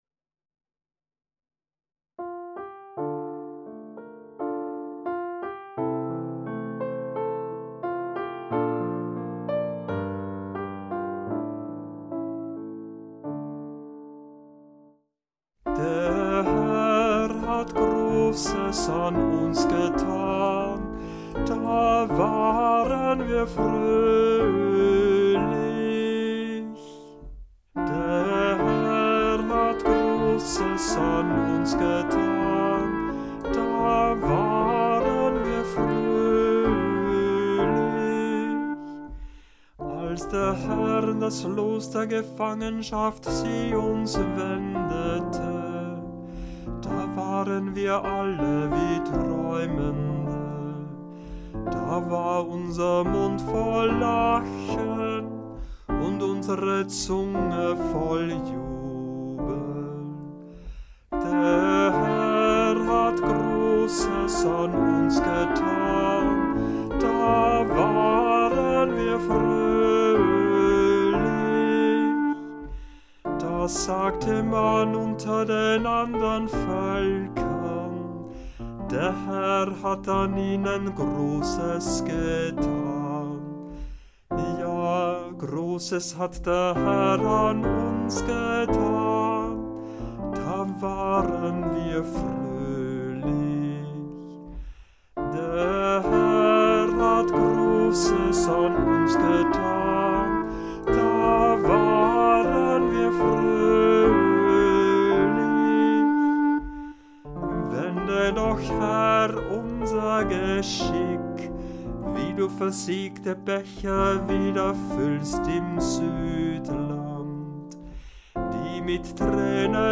Auswahl und Hörbeispiele aus verschiedenen Kantorenbüchern
Psalmen aus dem Gurker Psalter für Kantor mit Orgel- oder Gitarrenbegleitung finden Sie hier, geordnet nach den Lesejahren ABC und den Festen bzw.